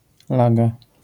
wymowa:
IPA[ˈlaɡa], AS[laga]